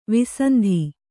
♪ visandhi